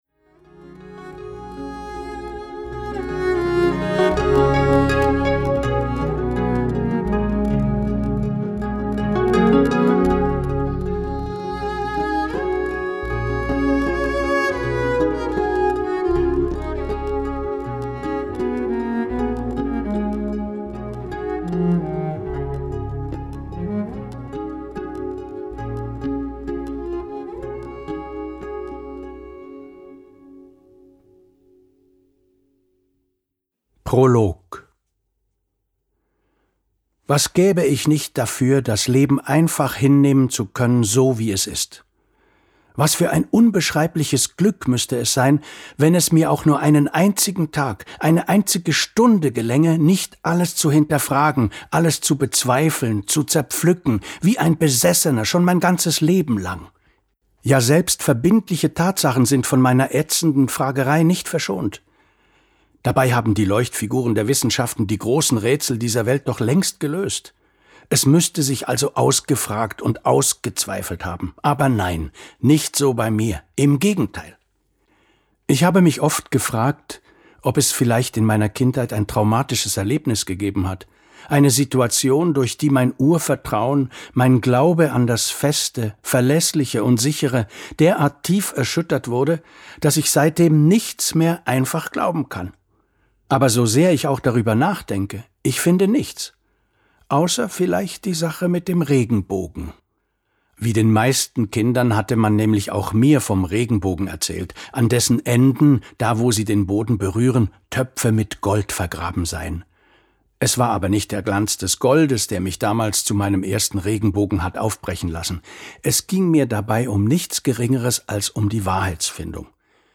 Hörbuch
Verschaffe Dir einen Eindruck durch einige Ausschnitte des Hörbuchs...